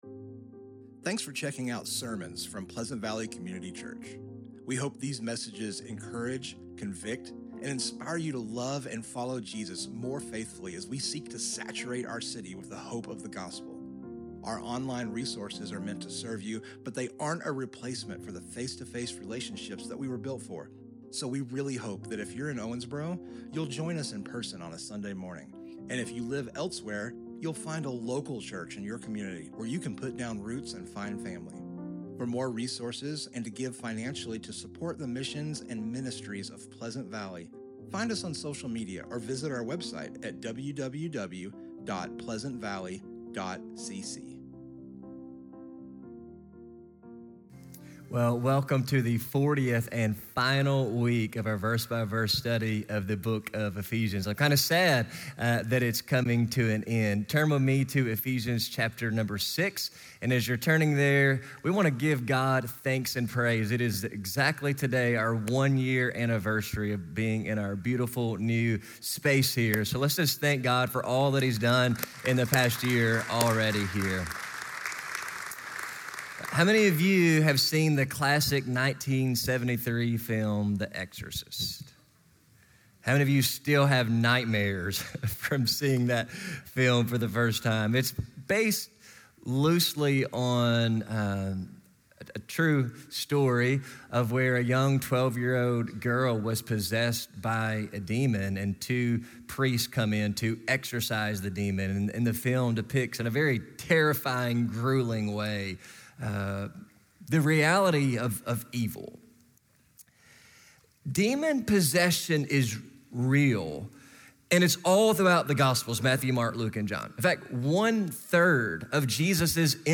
Book: Ephesians | Sermon Topic: